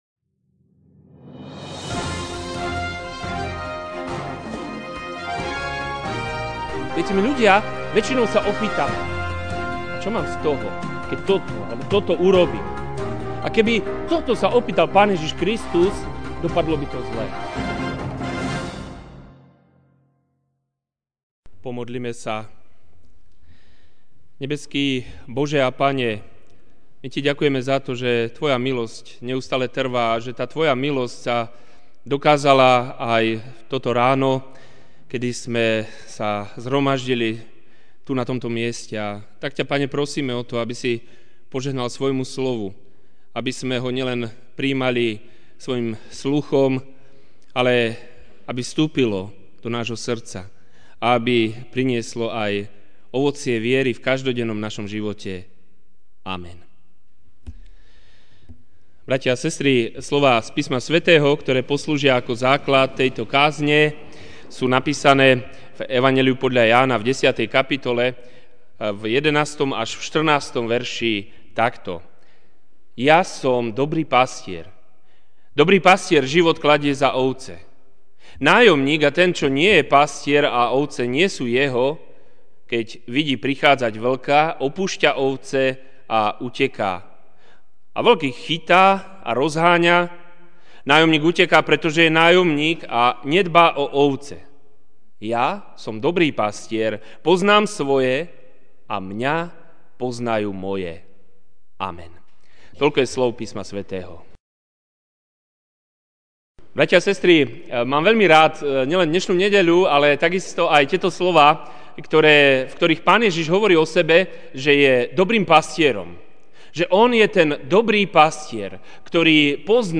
Ranná kázeň: Náš egoizmus vs Ježišova láska (Ján 10, 11-14) Ja som dobrý pastier.